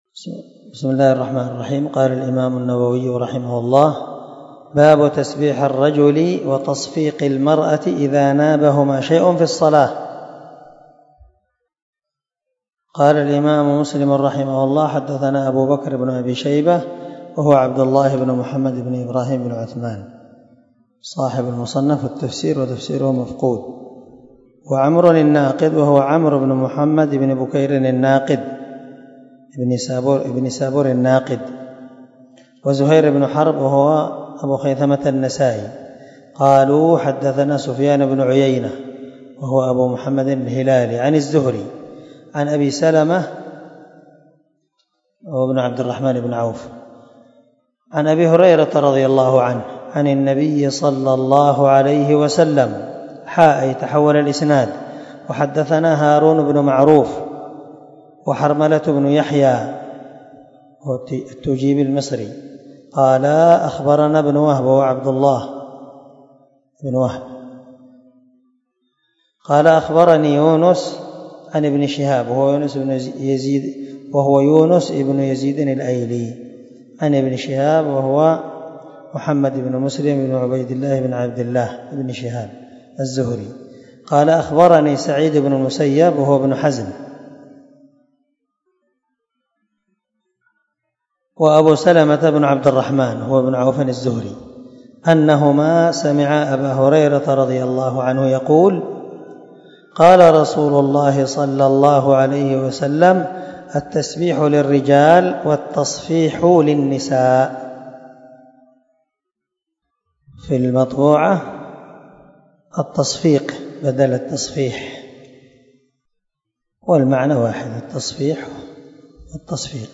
292الدرس 36 من شرح كتاب الصلاة حديث رقم ( 422 ) من صحيح مسلم
دار الحديث- المَحاوِلة- الصبيحة.